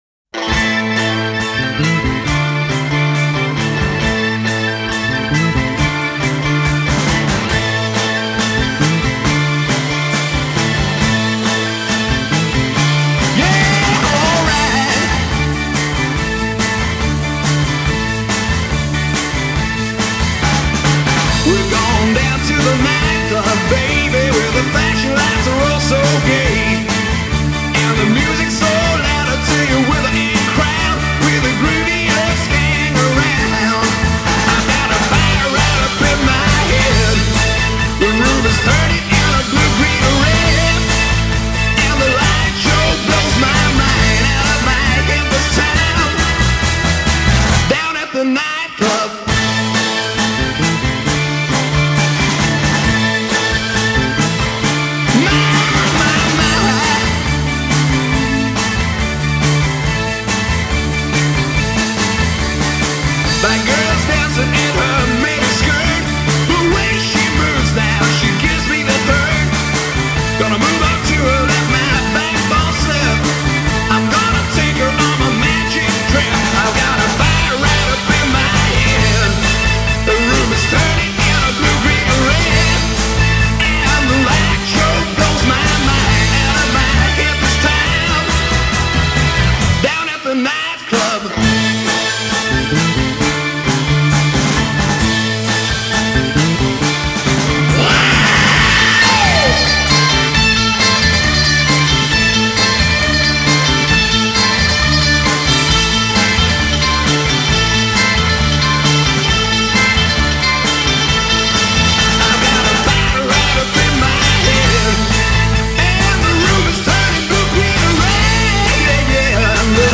The unexpected coda is especially delightful …